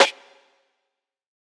Too Late Snare.wav